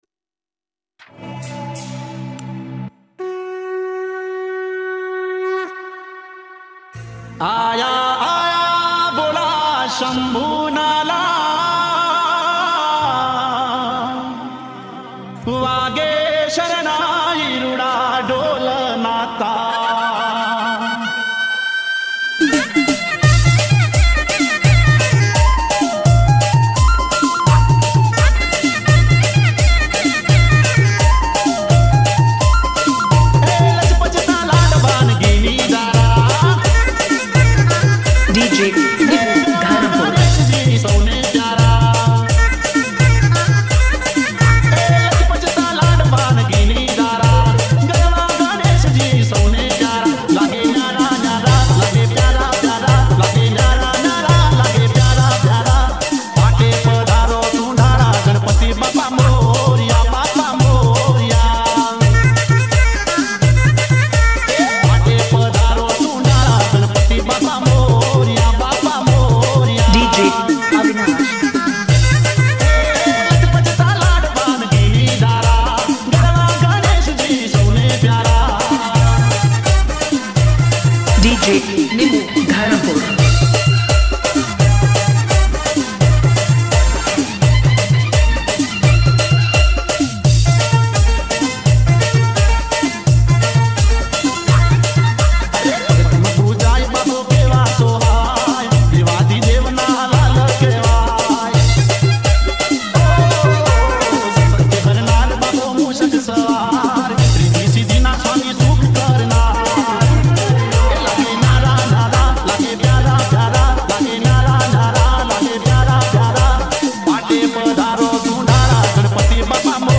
Dj Remix Gujarati